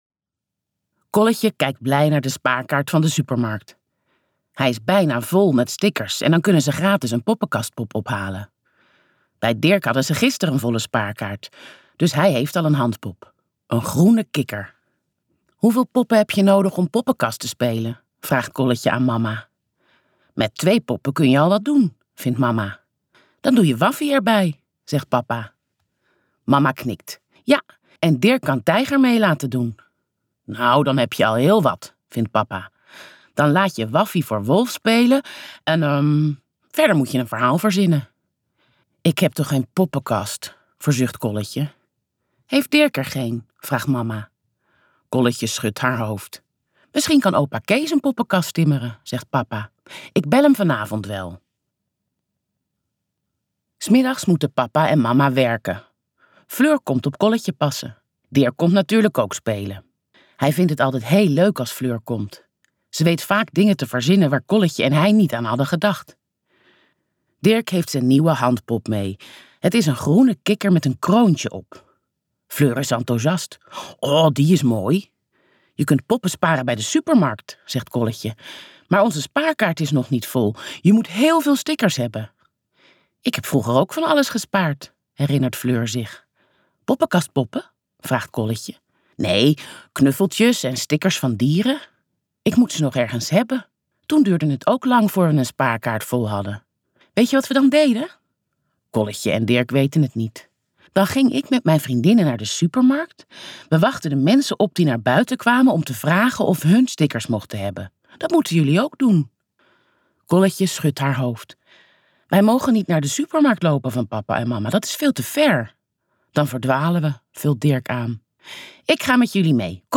In het luisterboek Poppenkast van Pieter Feller geven Kolletje en Dirk een geweldige poppenkastvoorstelling!
Luister het gratis fragment Poppenkast Auteur: Pieter Feller Direct bestellen ➔ Bekijk de voorkant In het luisterboek Poppenkast van Pieter Feller geven Kolletje en Dirk een geweldige poppenkastvoorstelling! Bij de supermarkt kun je poppenkastpoppen sparen.